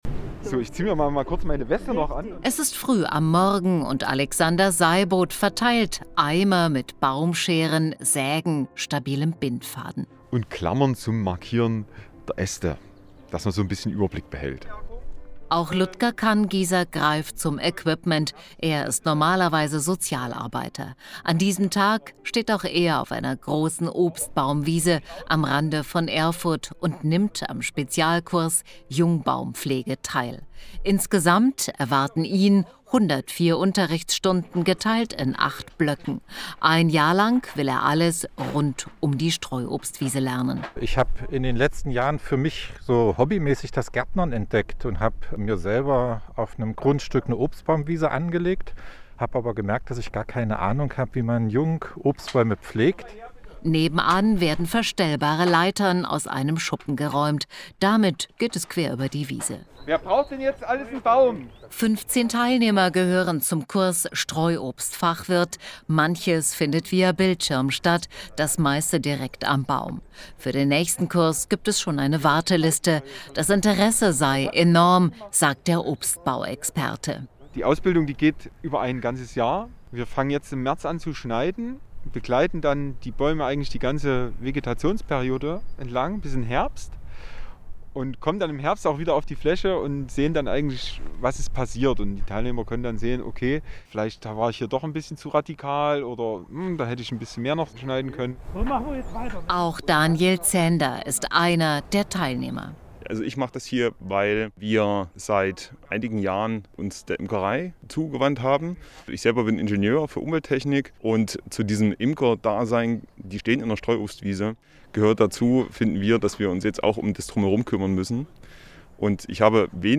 Rein hören – Streuobstfachwirtin on air!